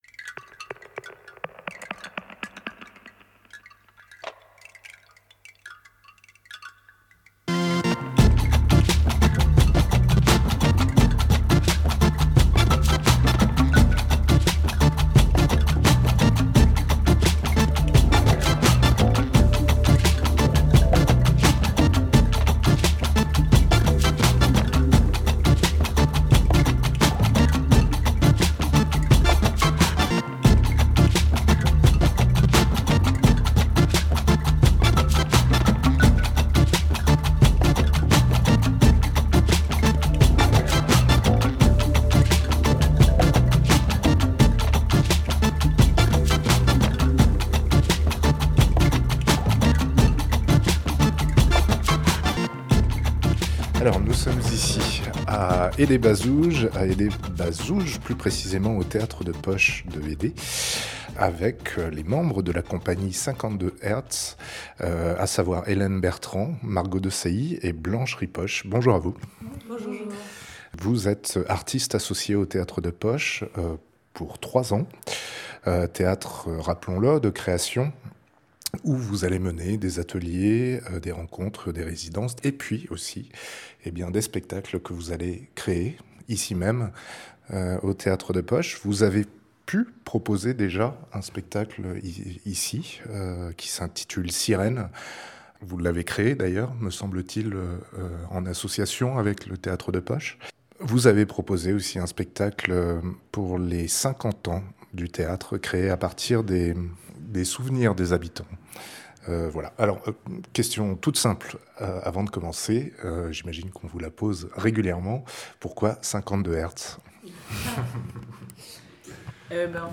Entretien avec